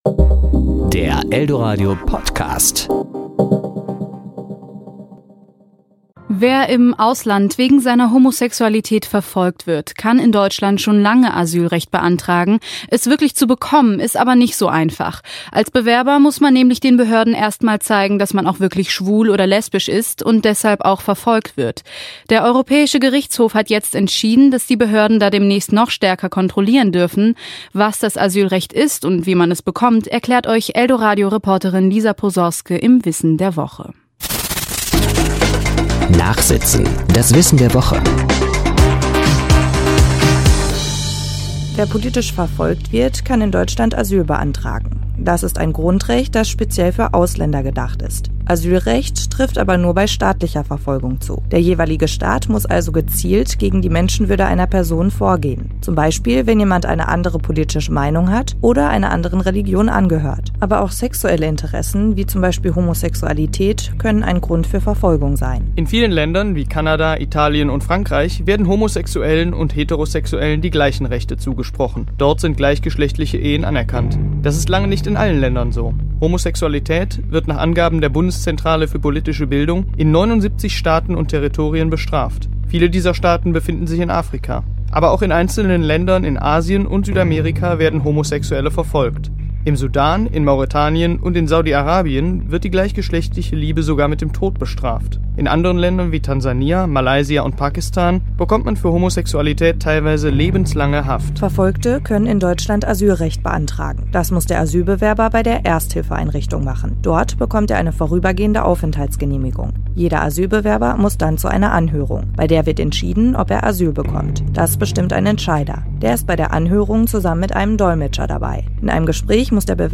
Der europäische Gerichtshof hat jetzt entschieden, dass die Behörden da demnächst noch stärker kontrollieren dürfen. Was das Asylrecht ist und wie man das bekommt erklärt euch eldoradio*-Reporterin